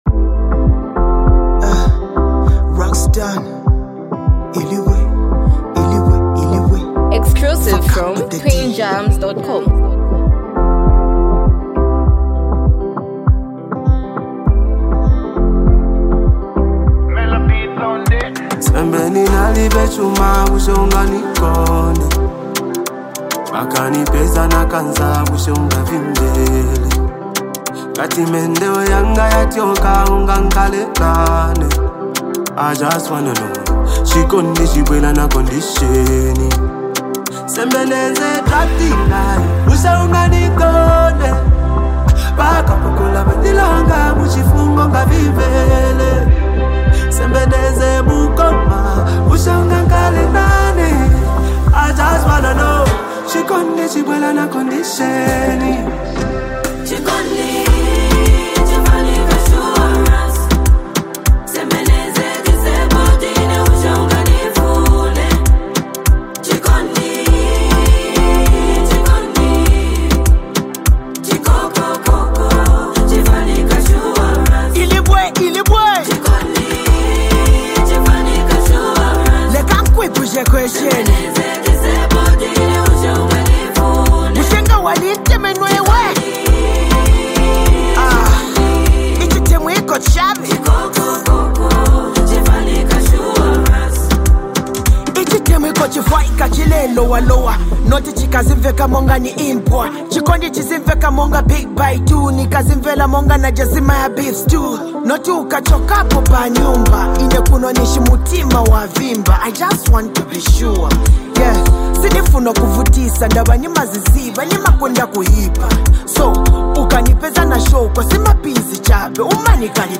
Highly multi talented act and super creative singer